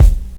INSKICK08 -L.wav